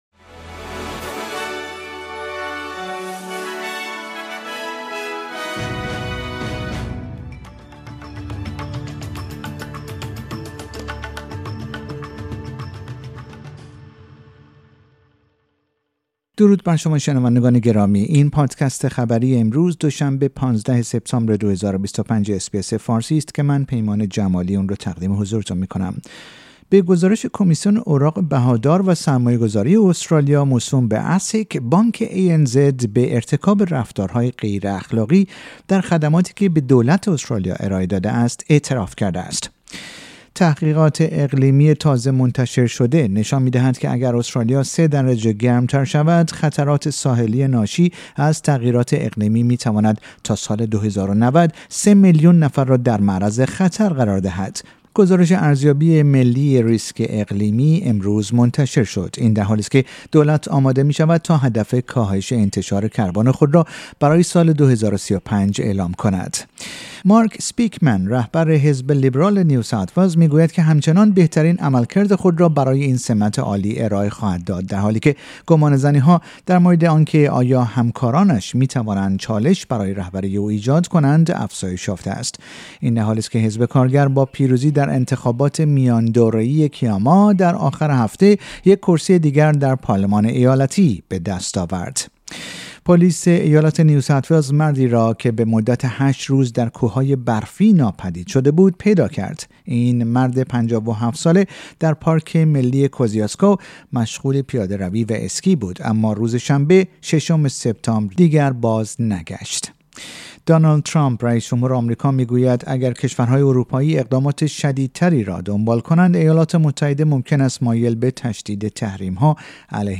در این پادکست خبری مهمترین اخبار روز دوشنبه ۱۵ سپتامبر ارائه شده است.